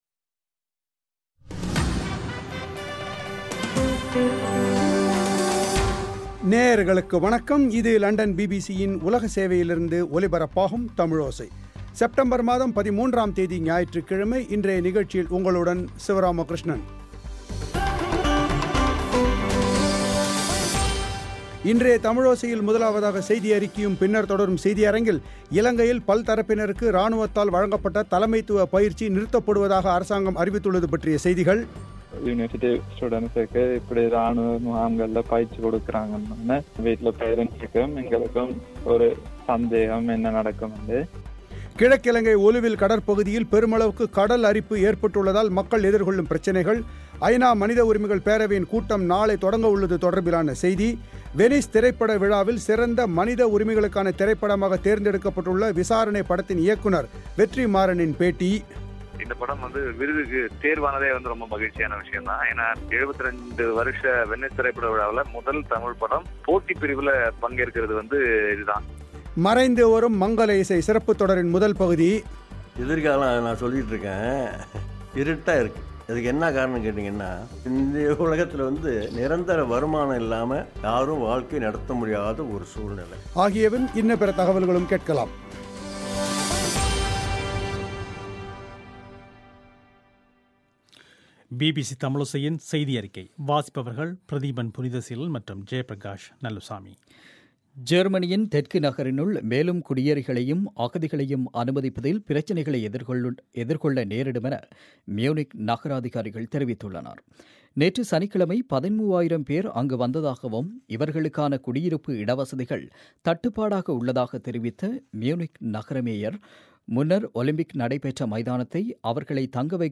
இலங்கையில் இராணுவத்தால் வழங்கப்பட்ட தலைமைத்துவ பயிற்சி நிறுத்தப்படுவதாக அரசு அறிவித்துள்ளது கிழக்கிலங்கை ஒலுவில் கடற்பகுதியில், பெருமளவுக்கு கடலரிப்பு ஏற்பட்டுள்ளதாக மக்கள் எதிர்கொள்ளும் பிரச்சினைகள். ஐநா மனித உரிமைகள் பேரவையின் கூட்டம் நாளை தொடங்கவுள்ளது தொடர்பிலான செய்தி வெனிஸ் திரைப்பட விழாவில் சிறந்த மனித உரிமைகள் திரைப்படமாக்த் தேர்தெடுக்கப்பட்டுள்ள விசாரணை படத்தின் இயக்குநர் வெற்றி மாறனின் பேட்டி மறைந்து வரும் மங்கல இசை சிறப்புத் தொடரின் முதல் பகுதி ஆகியவையும் இன்னபிற செய்திகளும் இடம்பெறுகின்றன.